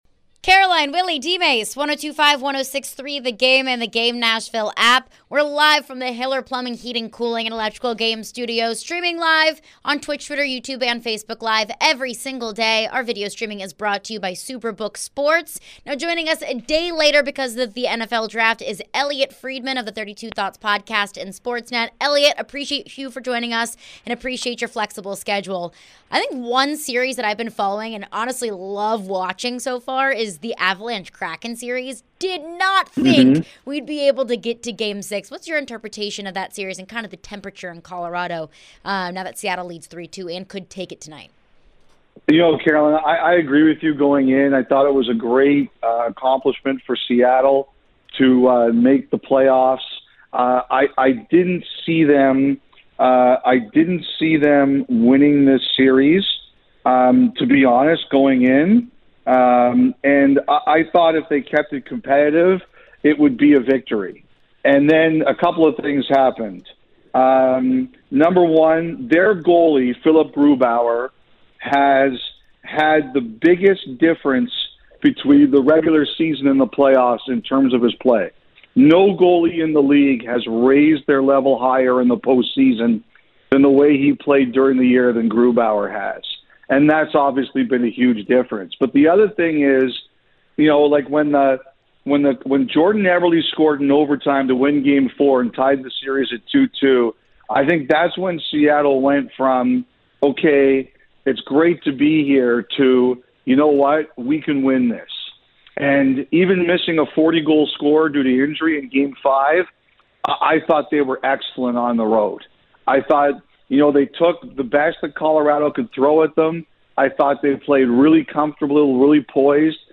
Elliotte Friedman Interview (4-28-23)